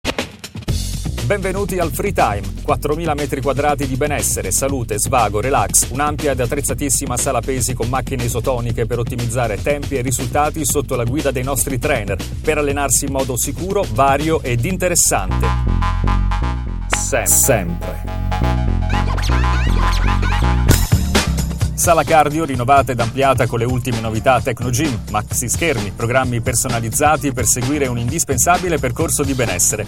Kein Dialekt
Sprechprobe: Werbung (Muttersprache):
Italian voice over artist.